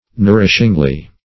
nourishingly - definition of nourishingly - synonyms, pronunciation, spelling from Free Dictionary Search Result for " nourishingly" : The Collaborative International Dictionary of English v.0.48: Nourishingly \Nour"ish*ing*ly\, adv.
nourishingly.mp3